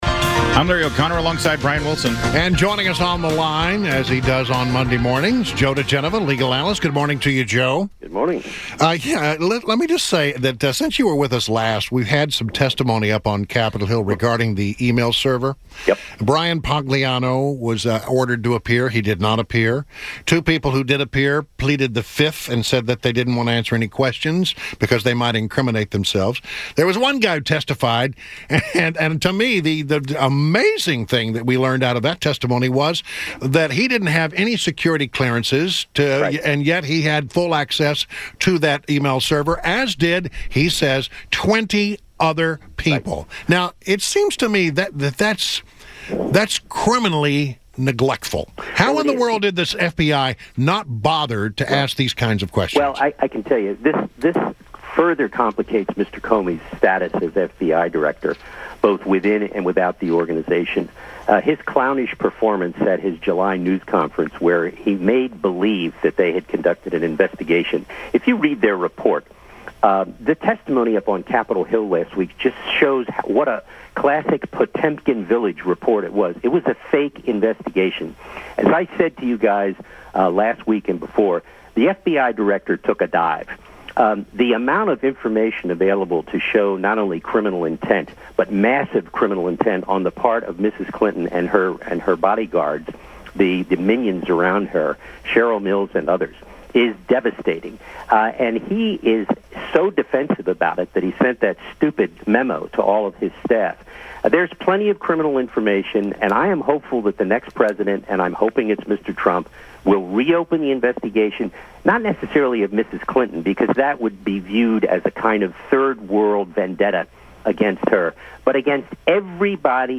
WMAL Interview - JOE DIGENOVA - 09.19.16
Legal analyst Joe diGenova joiend WMAL on Monday